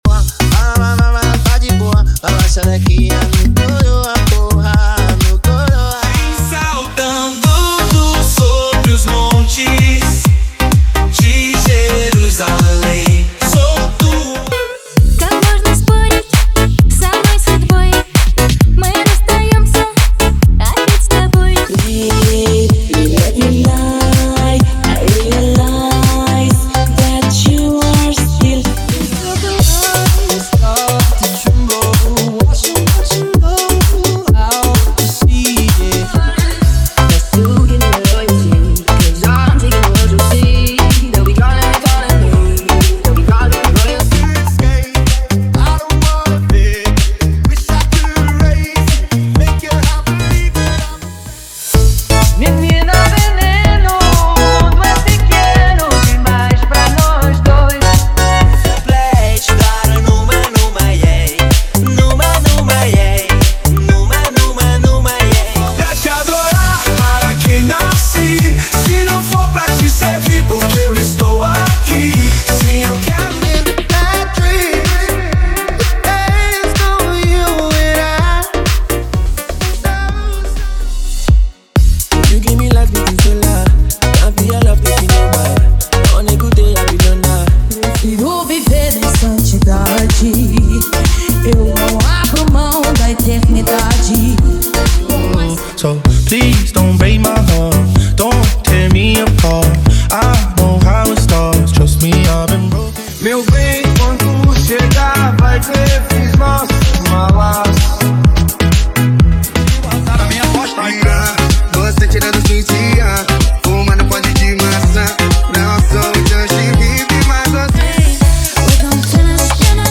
Dance Comercial: Prévias
– Sem Vinhetas